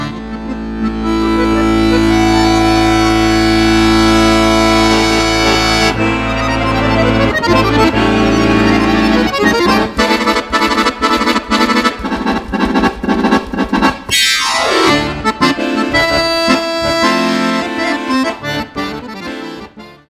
harmonikka accordion